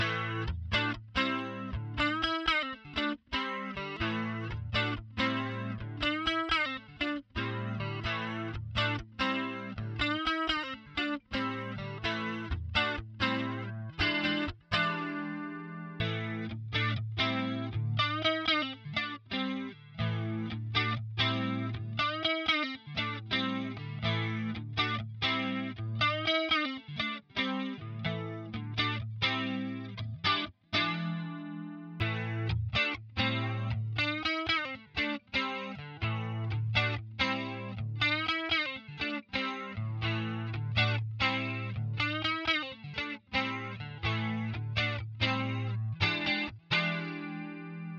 Hi, habe eben kurz mal was aufgenommen mit R120 Amp und Caps. 1 x Orginal Line 6 2 x Kostenlose IR Sagt mir ob 1